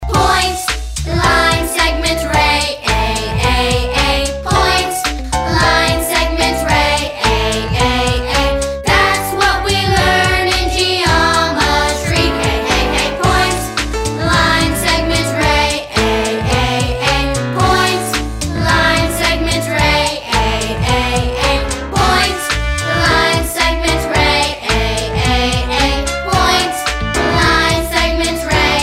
A geometry song.